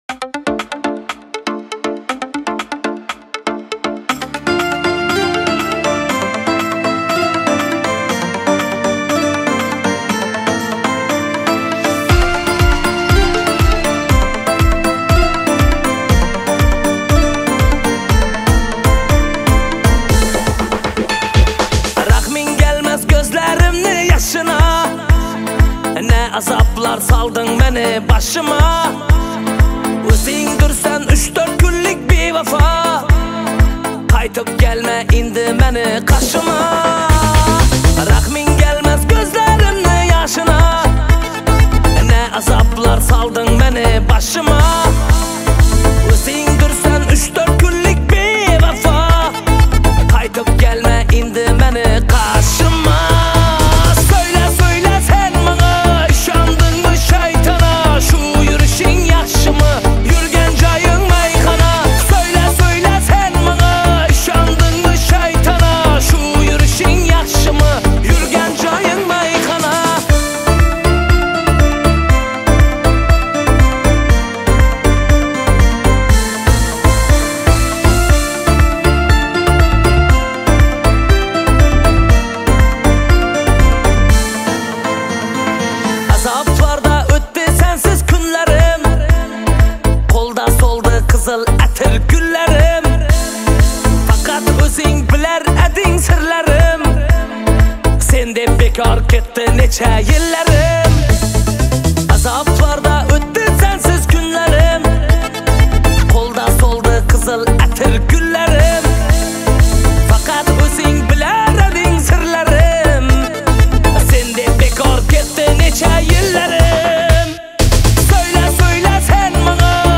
Хорезмские песни